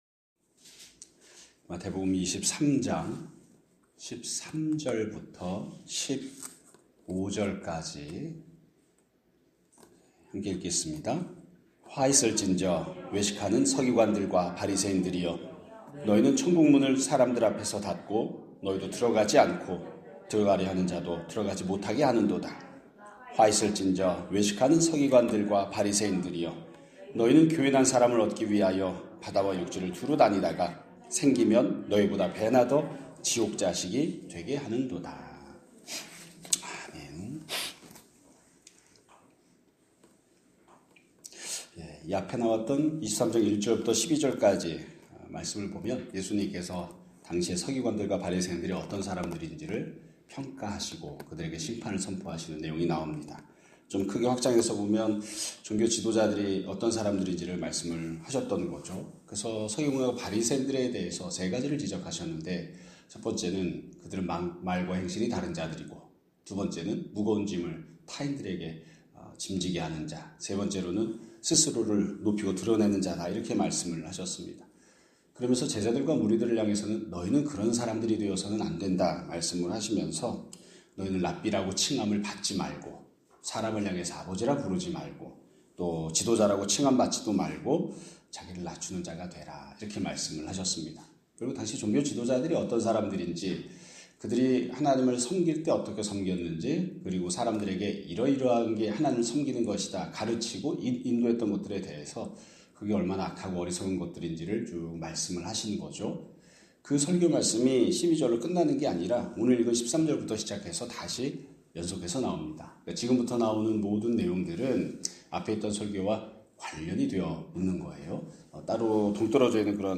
2026년 2월 23일 (월요일) <아침예배> 설교입니다.